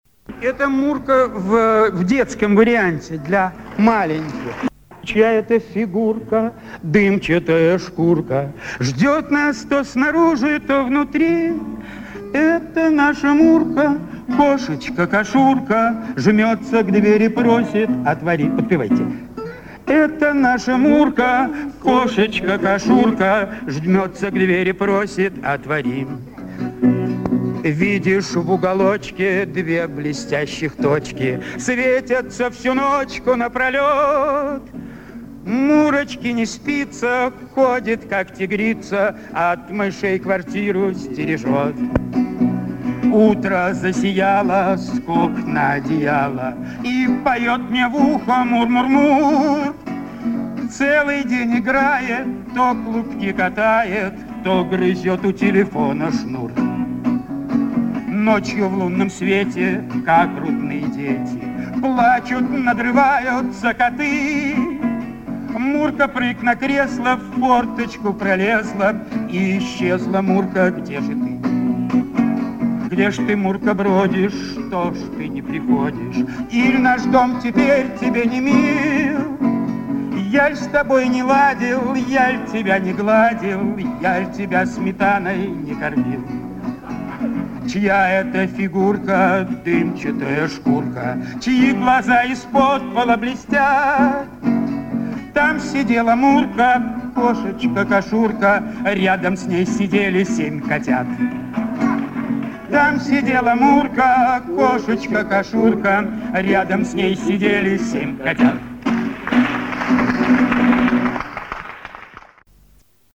пение